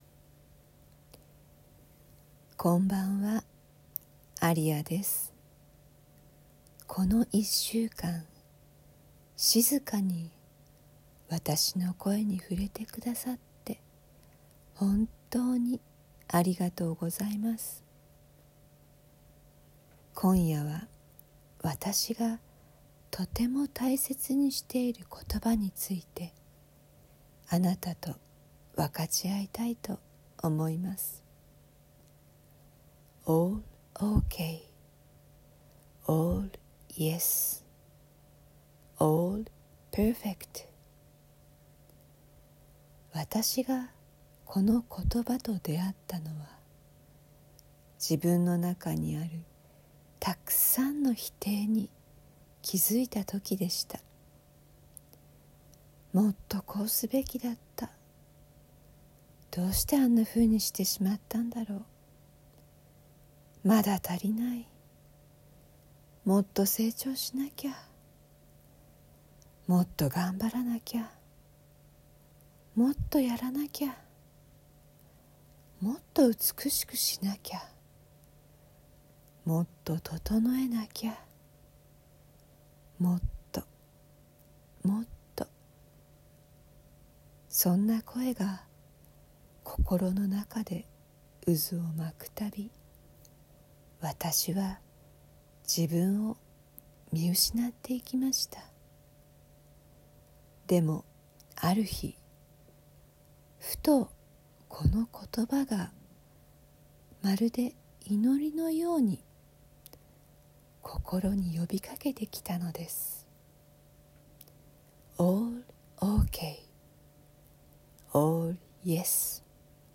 Whisper from Aria ｜ All OK. All Yes. All Perfect ──わたしという存在のままに - Whisper from Aria 静けさの声で整える音声リチュアル
Audio Channels: 1 (mono)